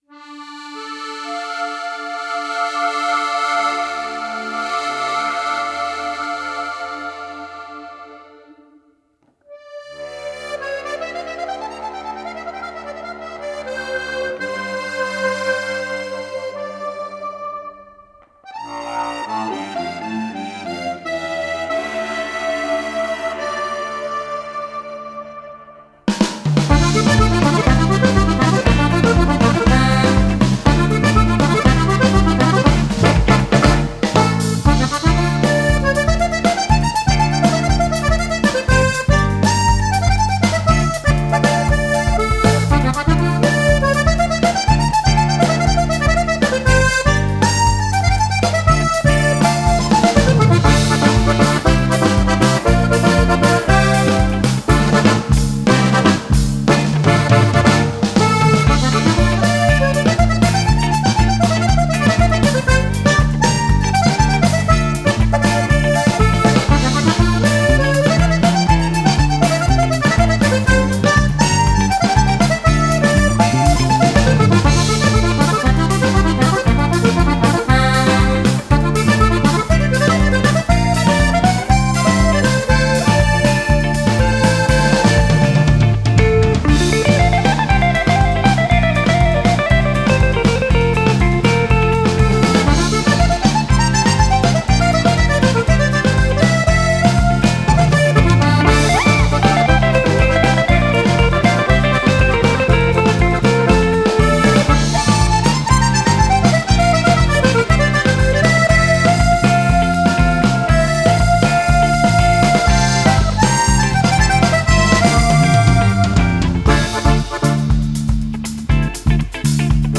instrumental quartet
jazz, blues, latin, funk